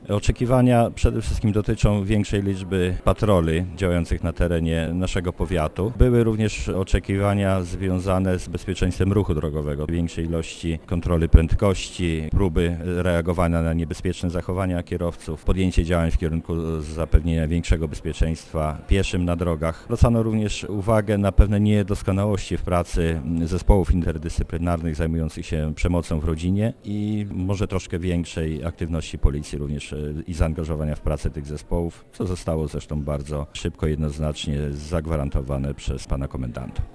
W Starostwie Powiatowym w Lublinie, w środę 30 października, odbyła się debata poświęcona bezpieczeństwu mieszkańców.
Uczestnicy debaty zwracali uwagę na kilka kwestii - mówi współgospodarz spotkania starosta lubelski Paweł Pikula: